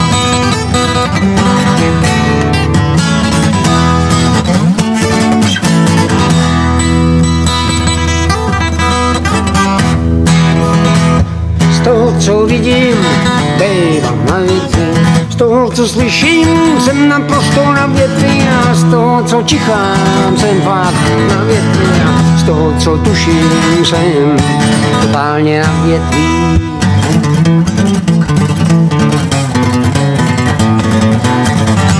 zpěv, kytary, foukací harmonika
vokály, klávesové nástroje, perkuse, bicí